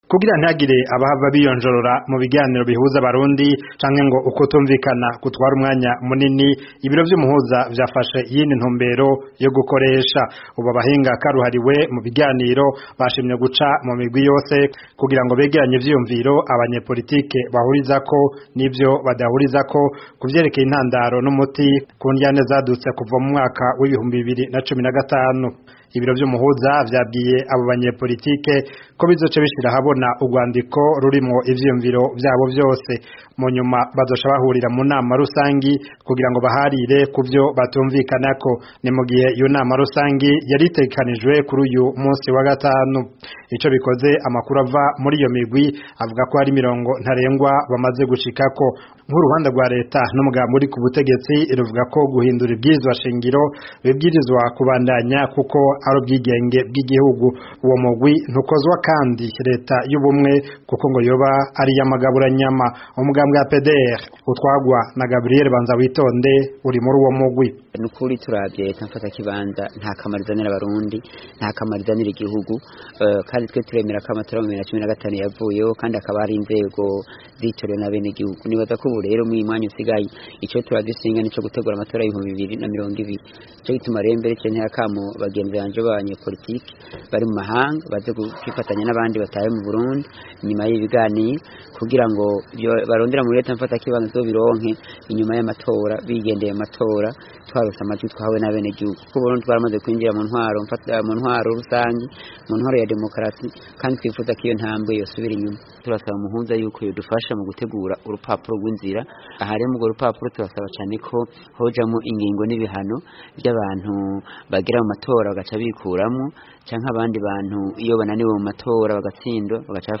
Inkuru